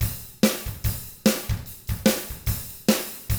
146ROCK T1-R.wav